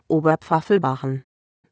Audio pronunciation of Oberpfaffelbachen.